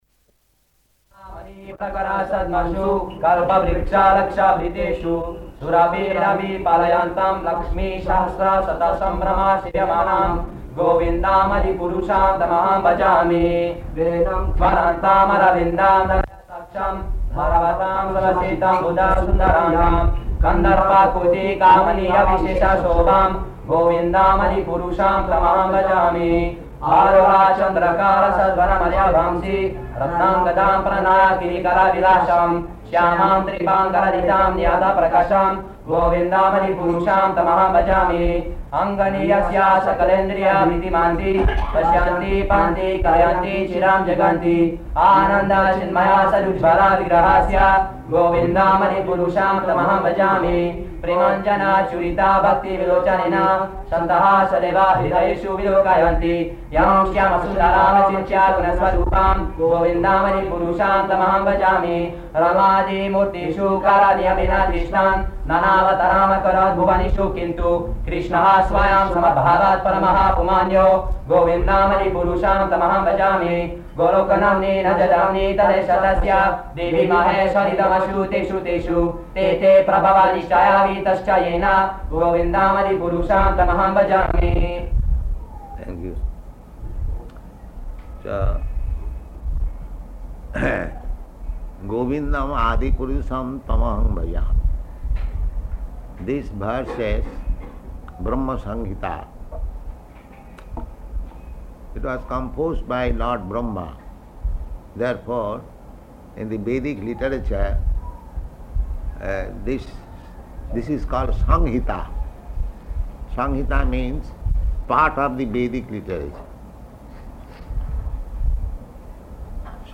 Location: Bombay
Devotees: [chant Brahma-saṁhitā verses]